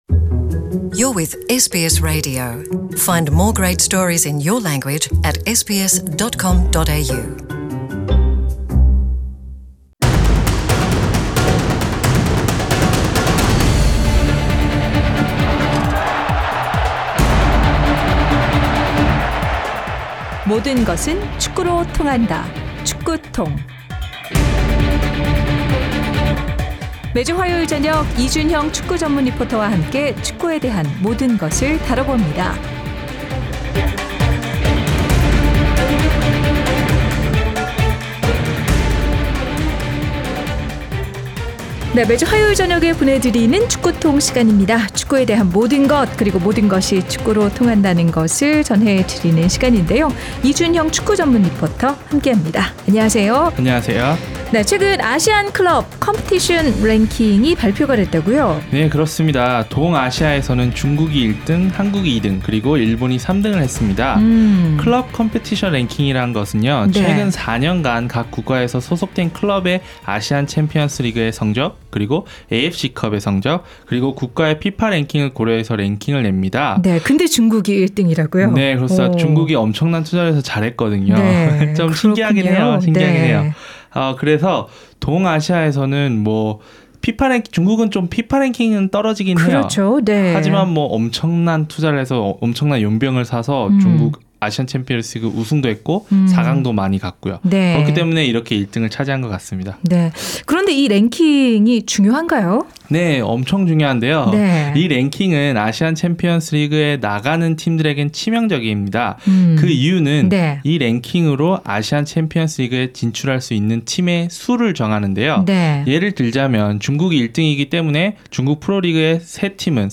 Soccer TONG is a sports segment that proves how everything leads to soccer.